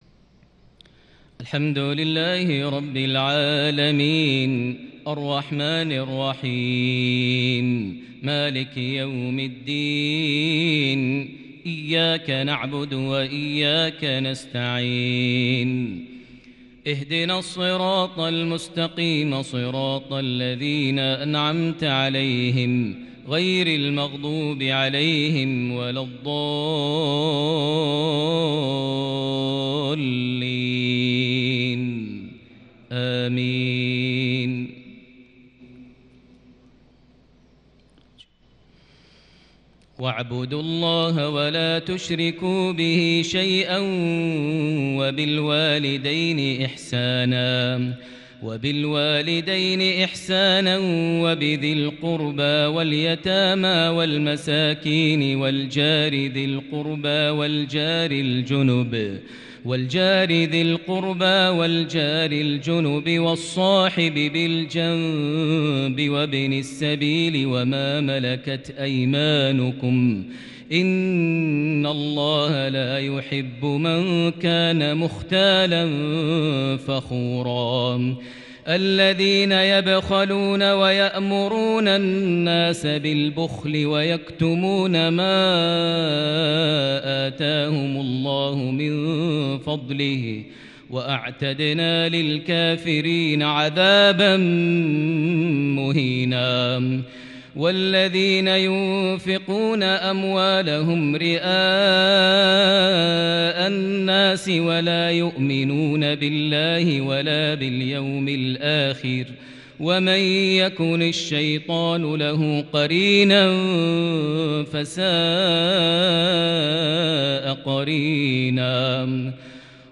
(..وجئنا بك على هؤلاء شهيدًا) ترتيل مؤثر بالكرد من سورة النساء (36-42) | 20 جمادى الآخر 1442هـ > 1442 هـ > الفروض - تلاوات ماهر المعيقلي